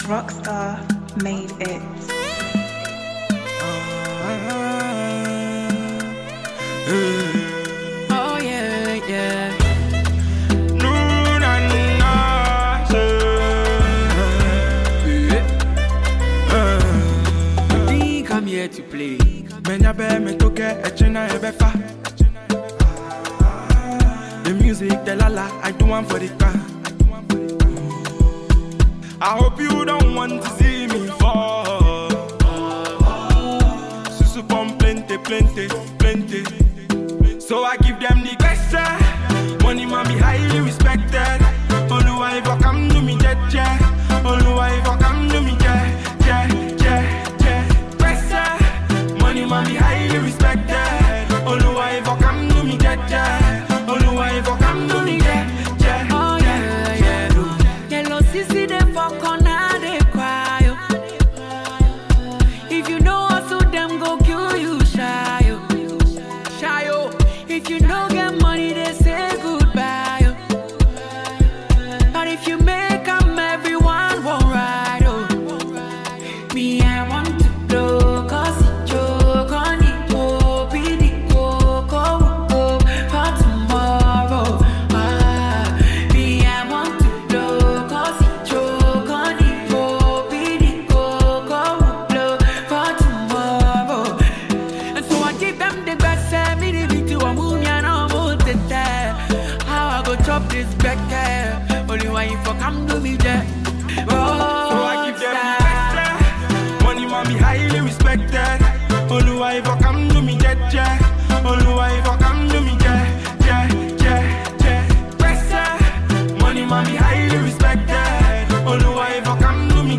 Ghanaian singer
highlife singer